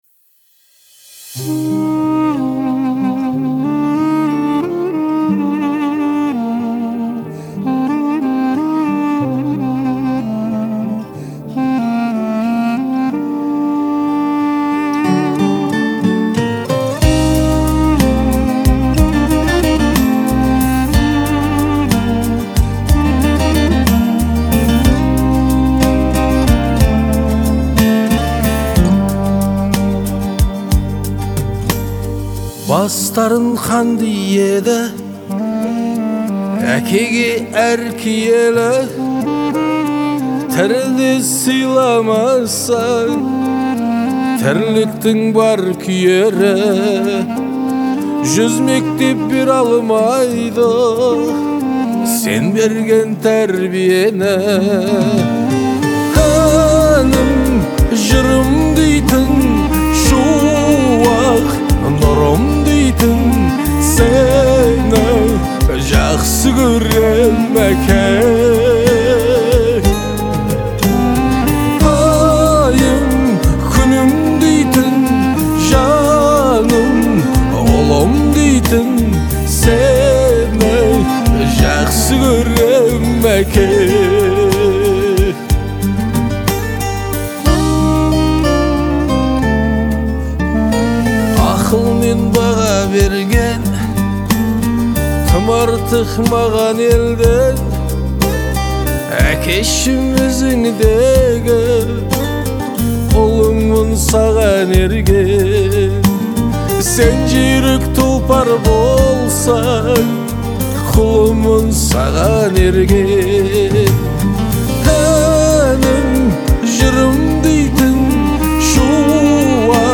Звучание отличается мягкостью и мелодичностью